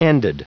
Prononciation du mot ended en anglais (fichier audio)
Prononciation du mot : ended